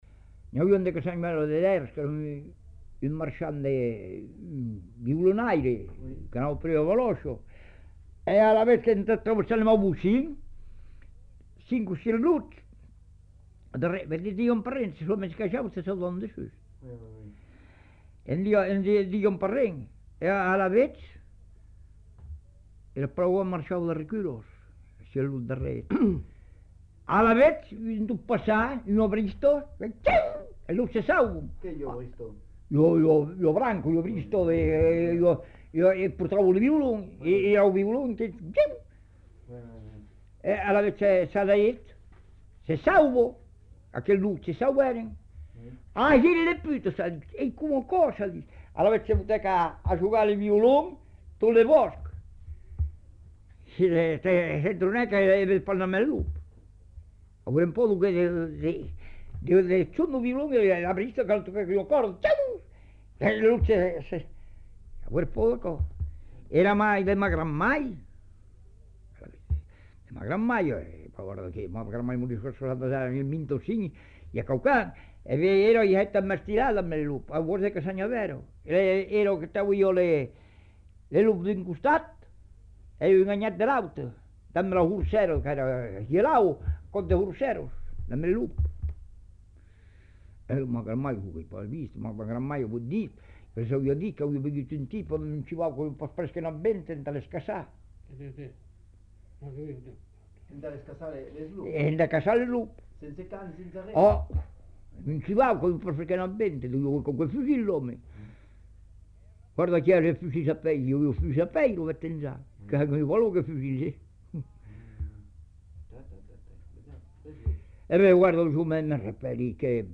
Aire culturelle : Savès
Genre : conte-légende-récit
Type de voix : voix d'homme
Production du son : parlé
Classification : récit de peur